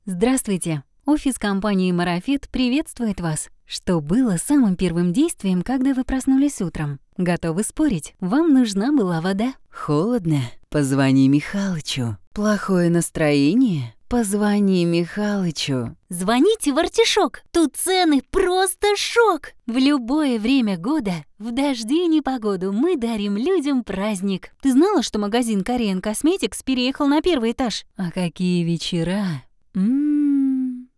Тракт: микрофон Rode NT1000 Звуковая карта Focusrite scarlett solo 2nd gen
Демо-запись №1 Скачать